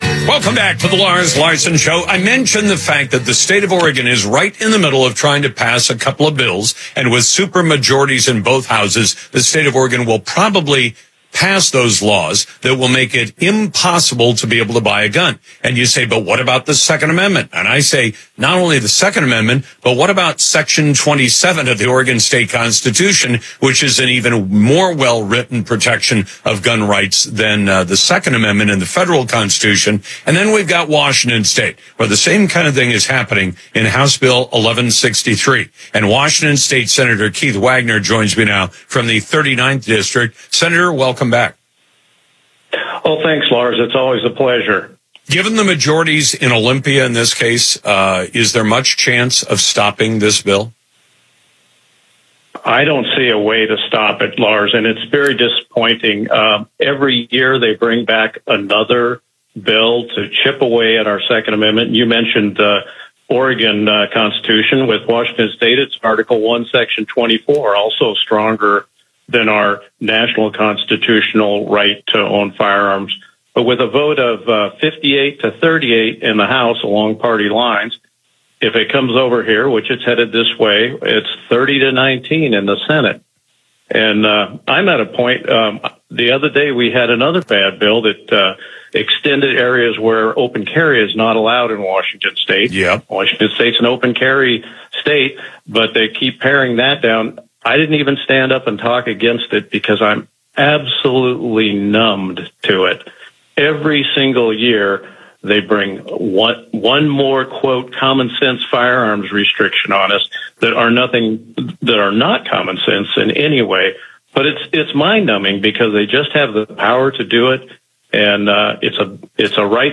SRC On Air: Sen. Wagoner on Lars Larson Show: New Gun Laws Hurt Citizens, Won’t Stop Crime - Senate Republican Caucus